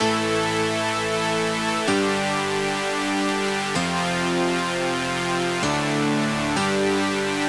VDE 128BPM Notice Bass 3 Root A.wav